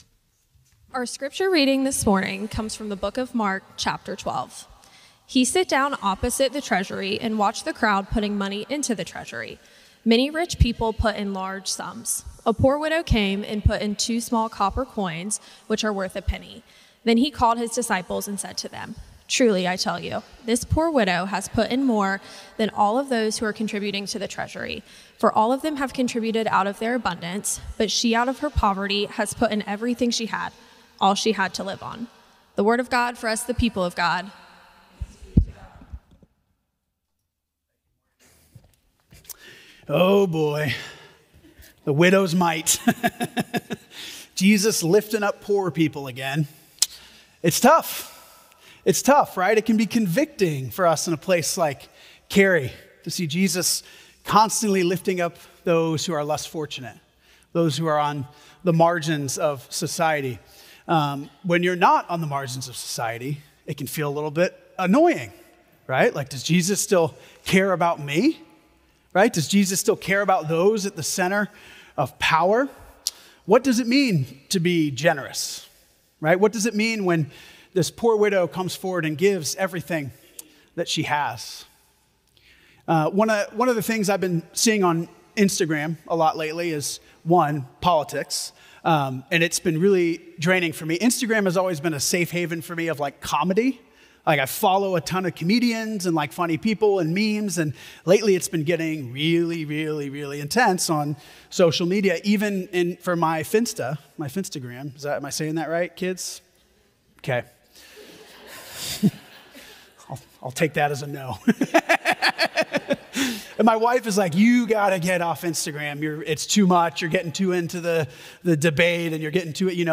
First Cary UMC's First on Chatham Sermon &ndash